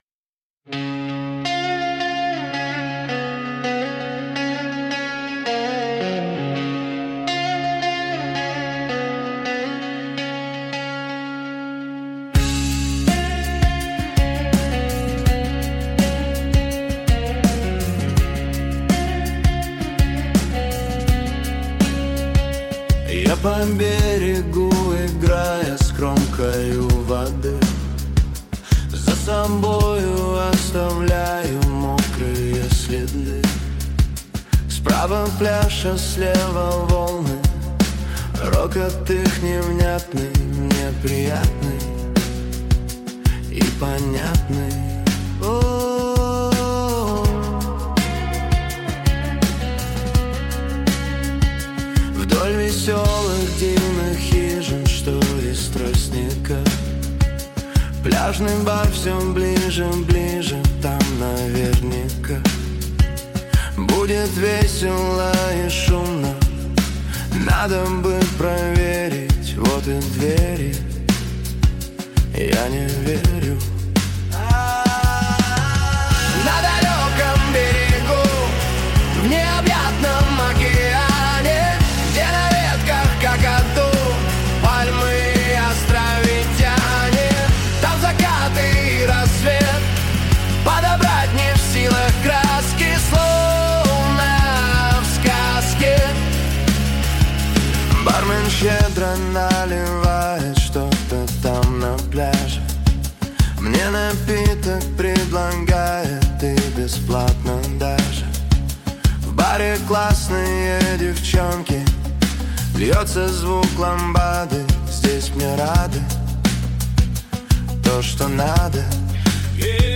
Песня хорошая, атмосферная! 👍🏼